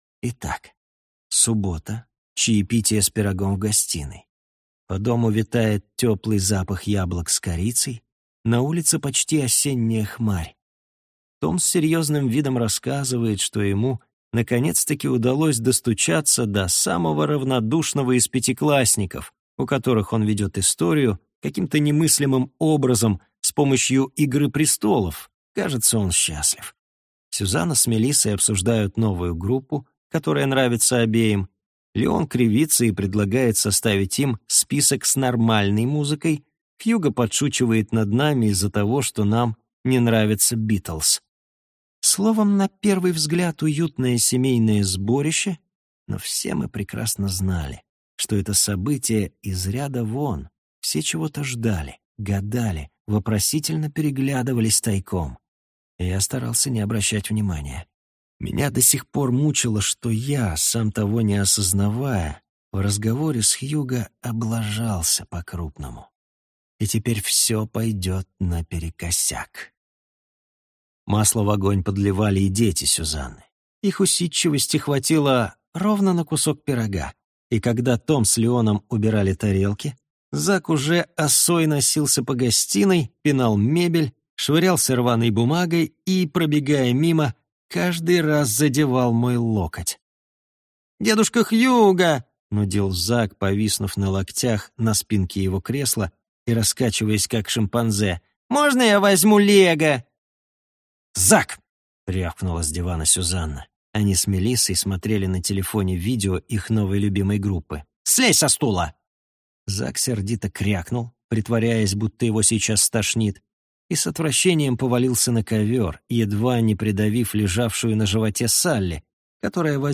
Аудиокнига Ведьмин вяз | Библиотека аудиокниг